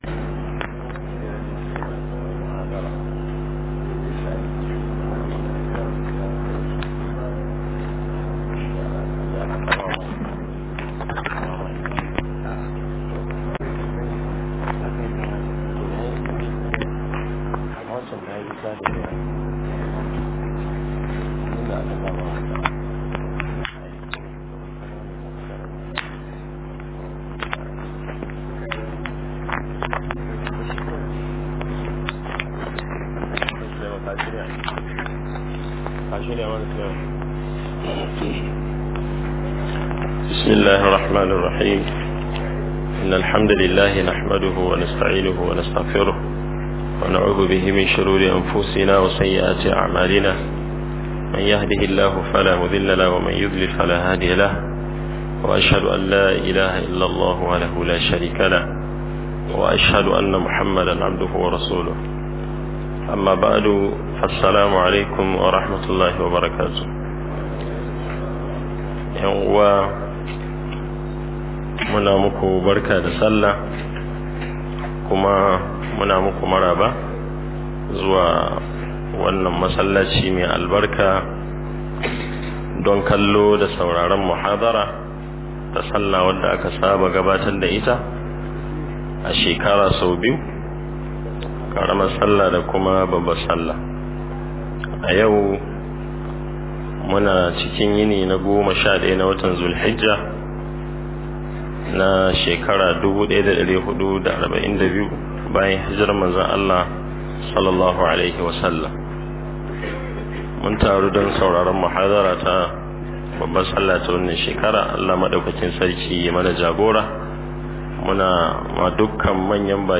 Mhdr kishin kasa a Merged files_0 - Muhadarori Da Lakcoci